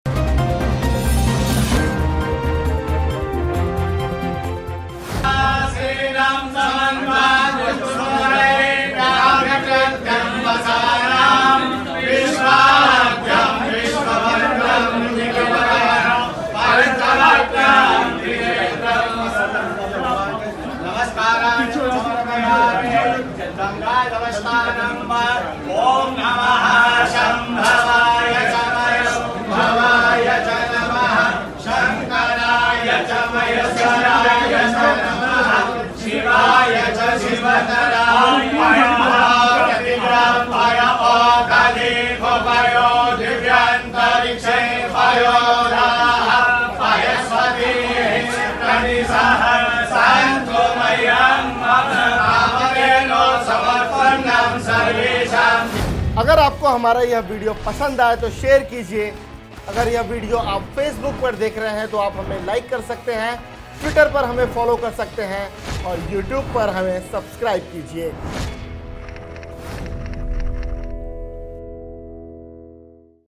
न्यूज़ रिपोर्ट - News Report Hindi / मध्य प्रदेश चुनाव : महाकाल के मंदिर पहुंचे कांग्रेस अध्यक्ष राहुल गाँधी